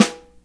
• Snare Drum E Key 04.wav
Royality free snare drum sample tuned to the E note. Loudest frequency: 1579Hz
snare-drum-e-key-04-CiW.wav